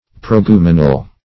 Search Result for " proeguminal" : The Collaborative International Dictionary of English v.0.48: Proeguminal \Pro`e*gu"mi*nal\, a. [Gr.
proeguminal.mp3